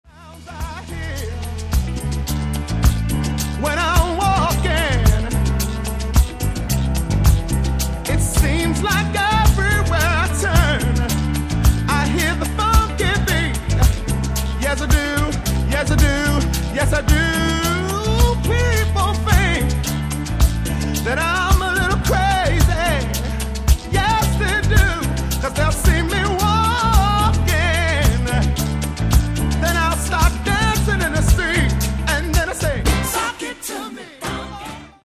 Genere:   RnB | Soul | Dance